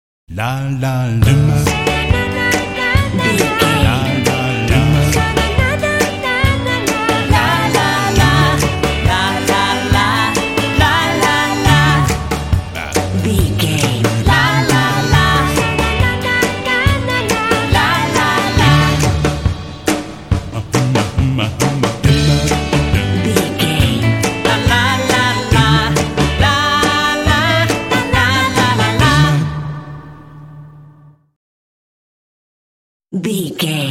Ionian/Major
D
happy
bouncy
groovy
vocals
drums
bass guitar
electric guitar
quirky
kitschy